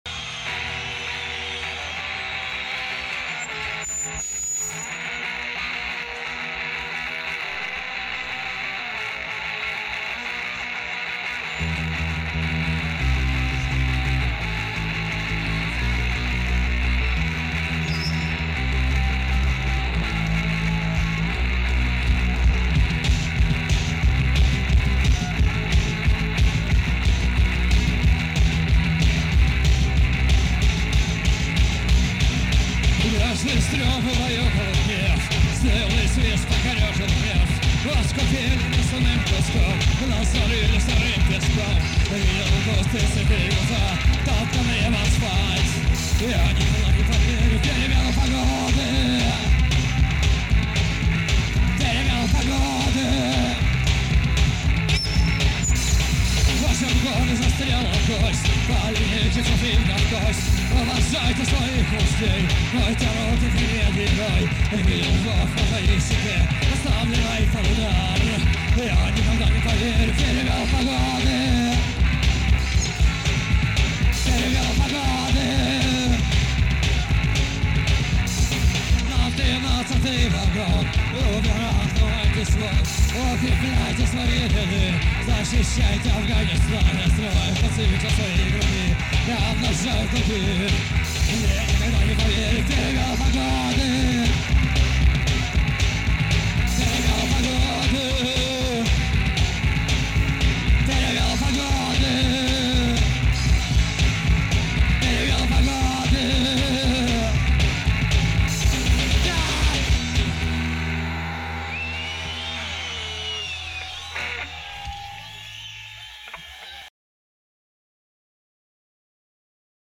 советская поэтесса, рок-певица и автор-исполнитель.
(Live)